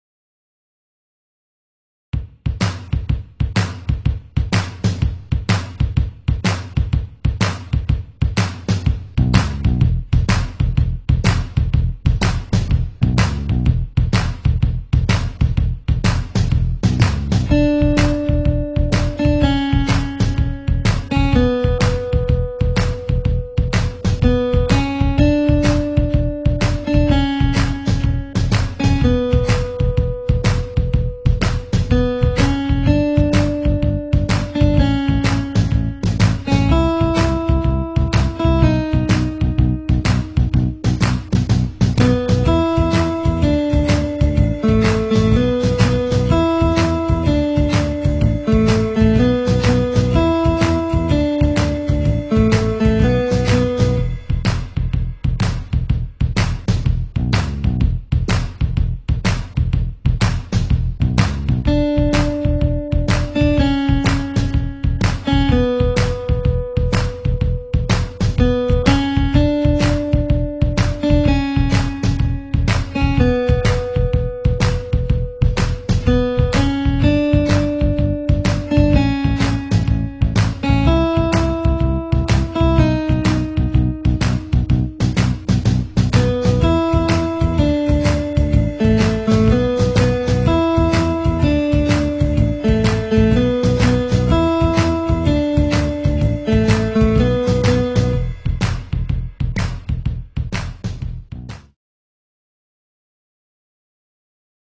آهنگ بی کلام چالش ی درام و گیتار